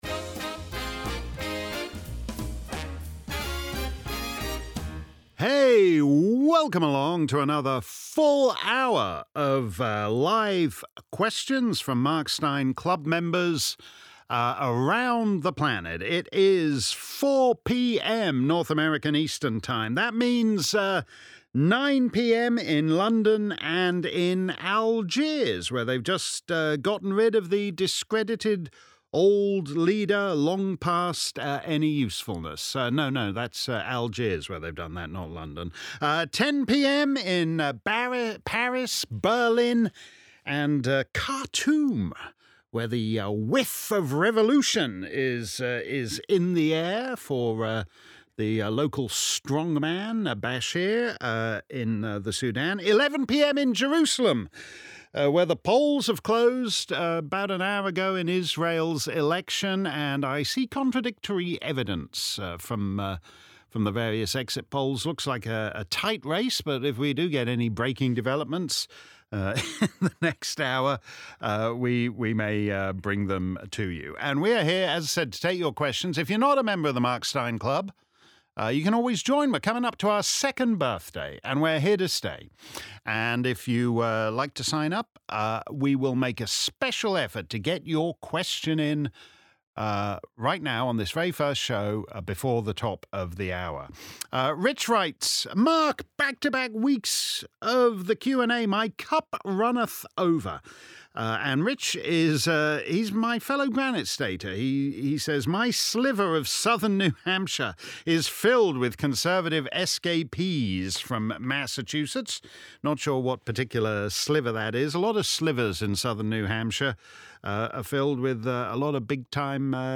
If you missed our livestream Clubland Q&A, here's the action replay.